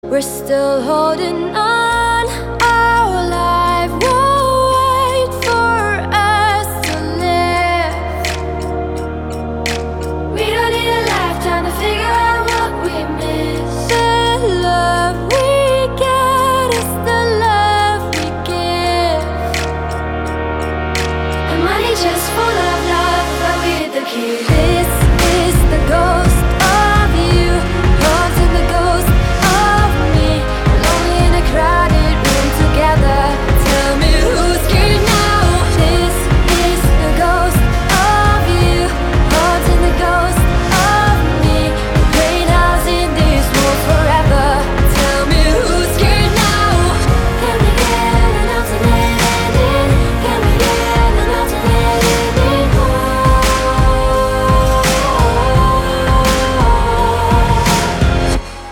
• Качество: 320, Stereo
красивые
женский вокал
спокойные